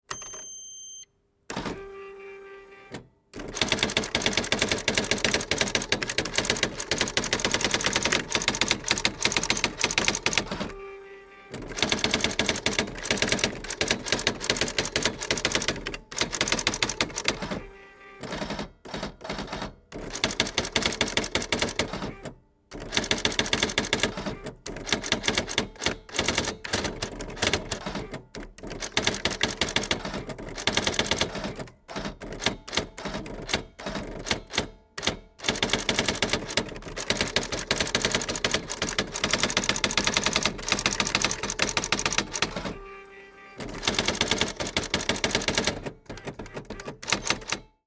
Elektro Triumph-Adler Gabriele 100 Typ EL typewriter